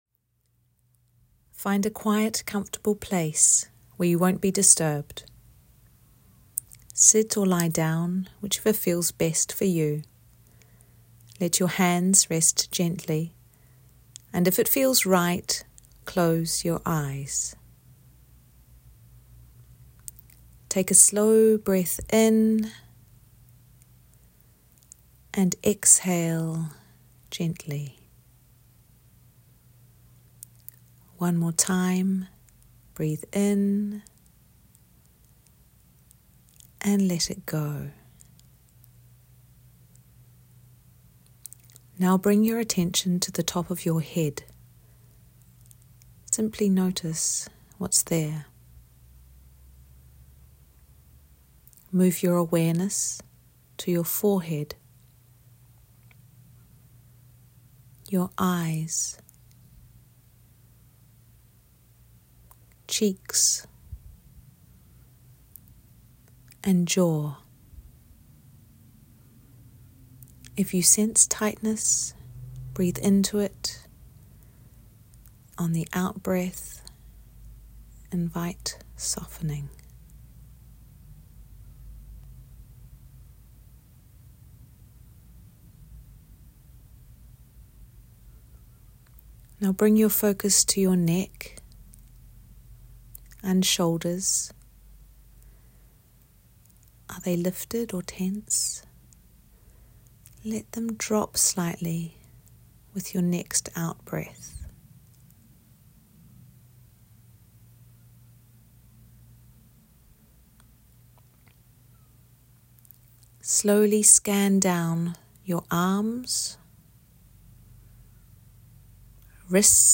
• Guided audio practices to calm your body and steady your mind
A 5-minute calming body scan to help you release tension and reset your nervous system.